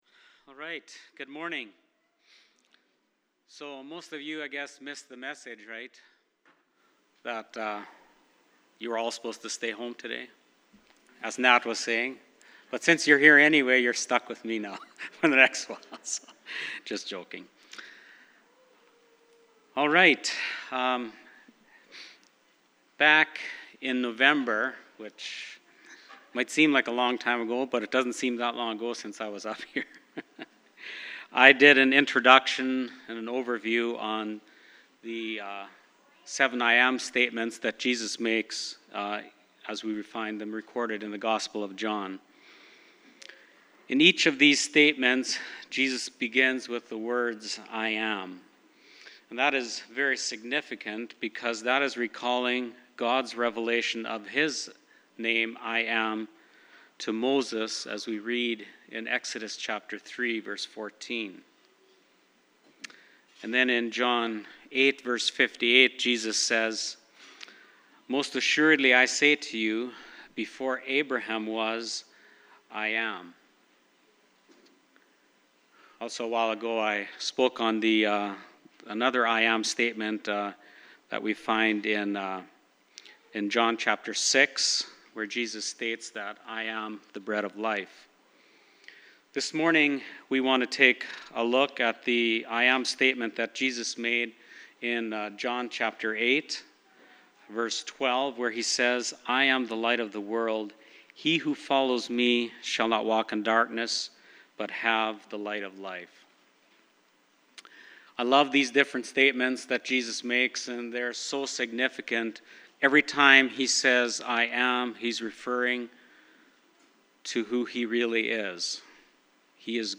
Passage: John 7:53-8:12 Service Type: Sunday Morning